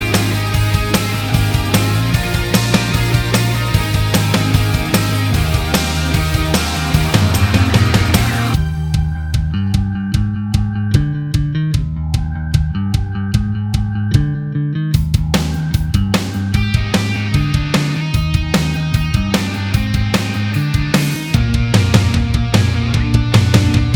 Minus All Guitars Pop (2010s) 2:49 Buy £1.50